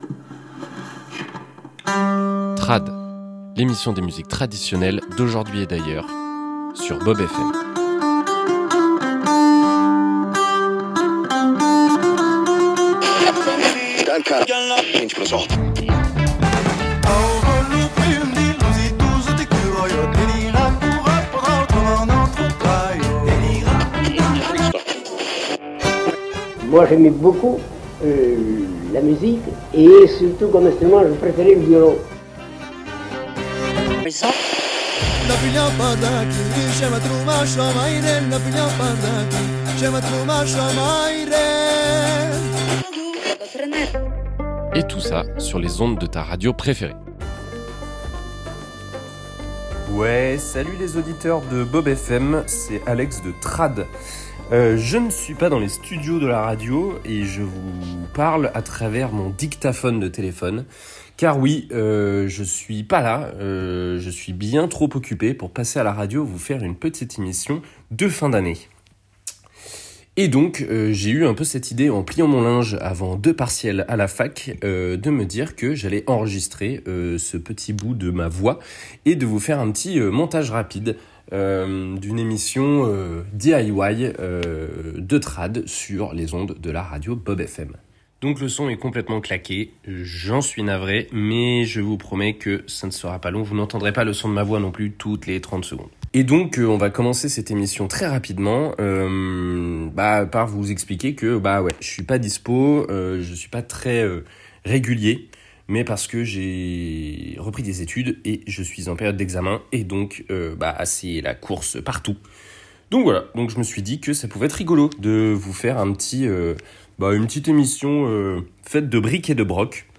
Alors c’est parti pour une émission à la maison, DIY radiophonique enregistrée sur un coup de tête au dictaphone claqué du portable (parce que pourquoi pas ? C’est pas ça l’esprit radio libre finalement ? Prendre en vol l’esprit d’initiative et de création ?).